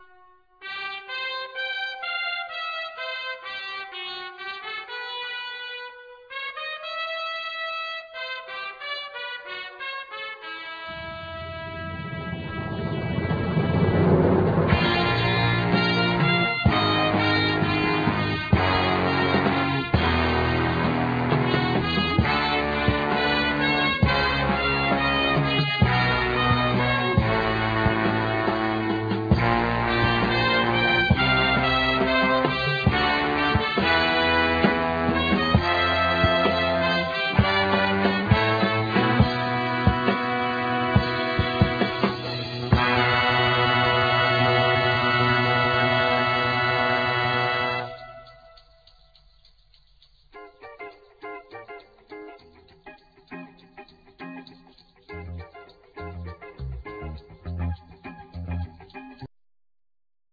Alto+Soprano sax
Piano,Keyboards
Guitar
Bass
Trumpet
Trombone
Drums
Vocals